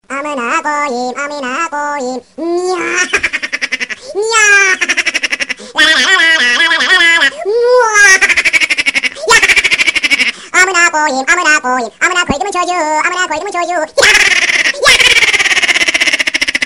la mwa lalala deformed laugh Category